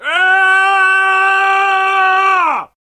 scream3.wav